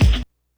kick04.wav